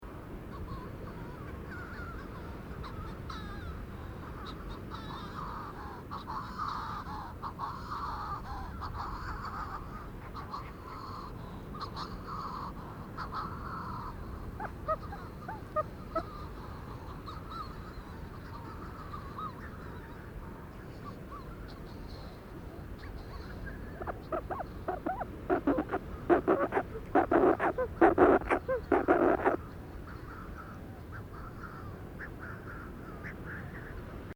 Manx Shearwater Recordings, July 2007, Co. Kerry, Ireland
strangle call + burrows